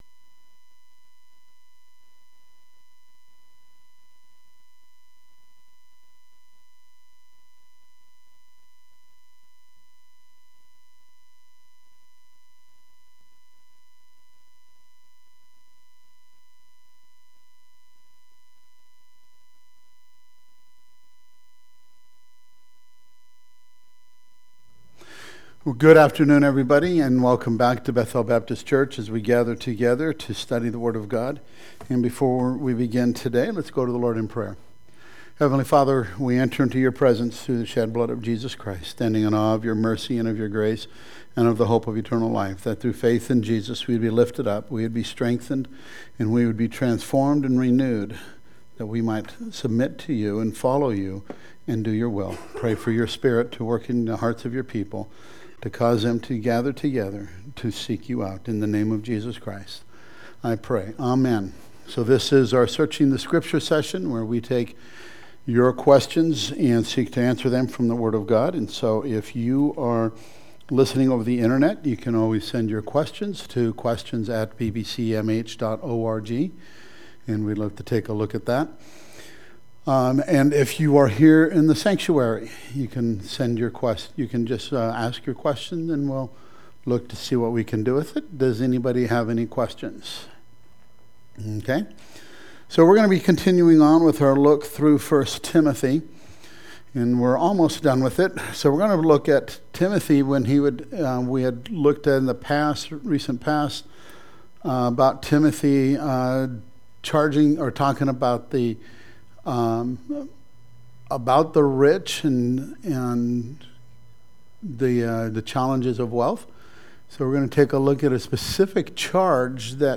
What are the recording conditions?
The "Searching the Scriptures" class consists of taking one or more questions that are brought up in the class or from class members, church members, church listeners, books and others that address any aspect of the bible.